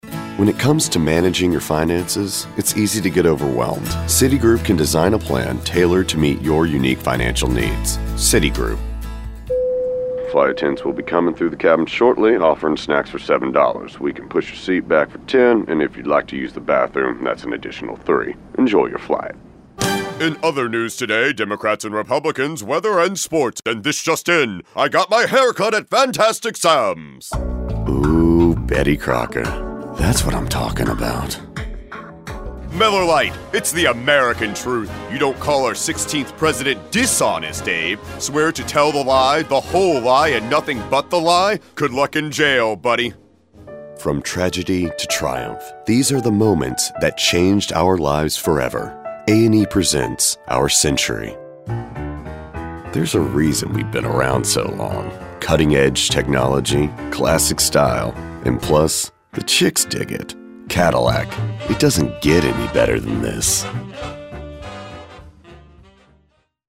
All of our contracted Talent have broadcast quality home recording studios.
Commercial Demo audio.mp3